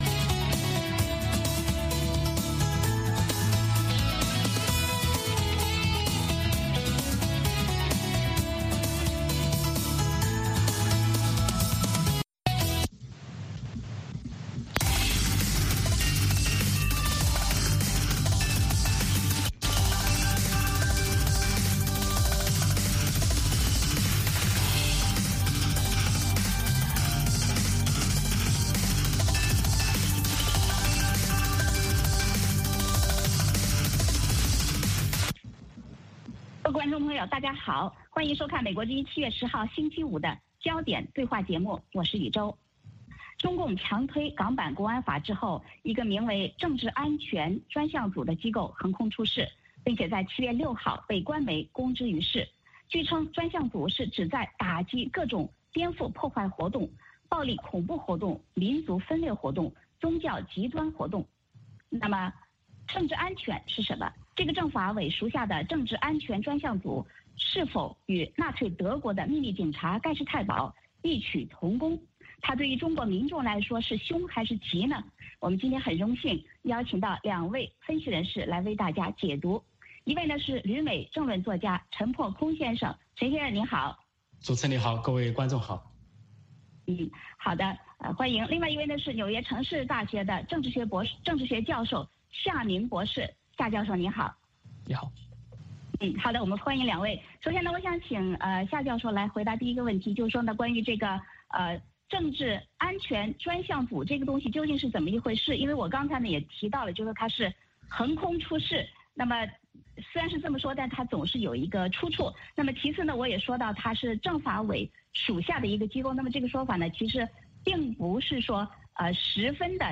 《焦点对话》节目追踪国际大事、聚焦时事热点。邀请多位嘉宾对新闻事件进行分析、解读和评论。